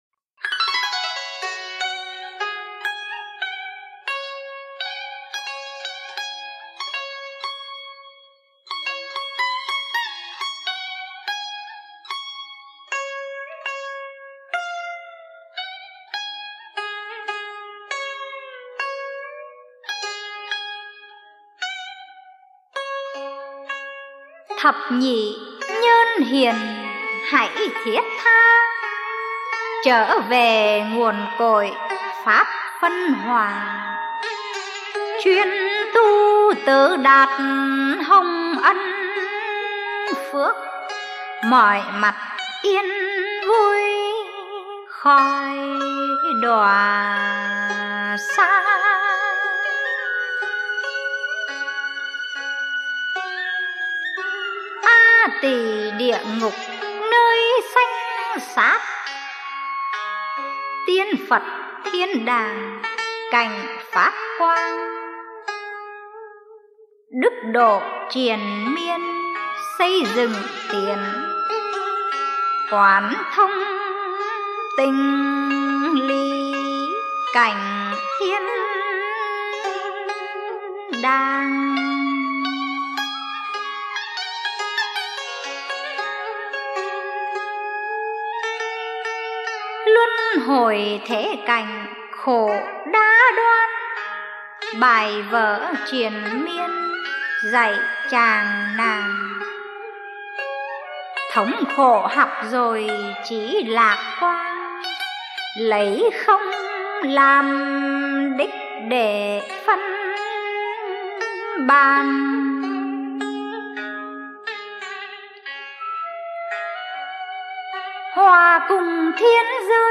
Thiền Ca Vô Vi - Dân Ca & Cải Lương